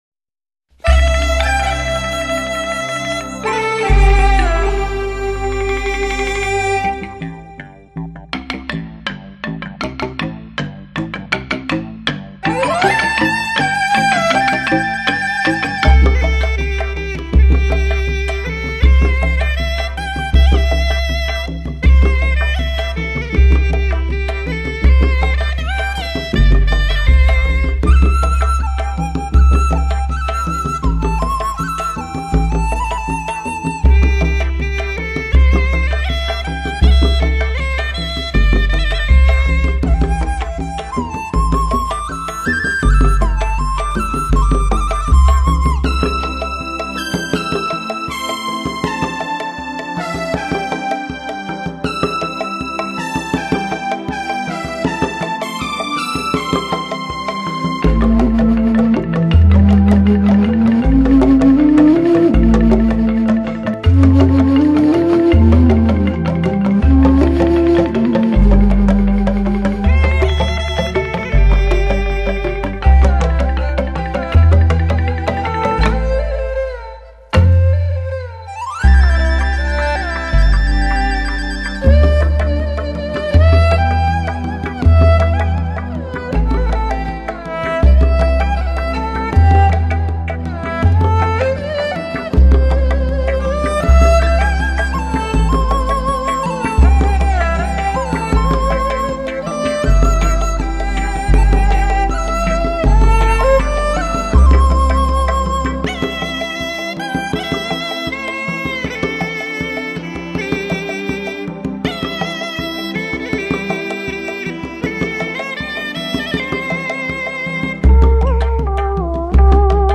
巨型竹筒墩地产生的超低频响。
浅淡优美的电子乐映衬着都市人的青葱梦想，那份感动，如伫立在喧嚣的入口，品味着渐远的纯真……
声音古朴醇厚，常会伴有夸张的吹奏气流声。
声音松透而富于弹性。
吹管乐器：管子 梆笛 巨型竹笛
弓弦乐器：大筒（竹二胡）
弹拨乐器：独弦琴
打击乐器：相 竹长沙筒 竹短沙筒 高音竹排筒 低音竹排筒 炭管琴 渔排鼓 高音竹板琴 低音竹板琴
湖北民歌) (低品质64k.wma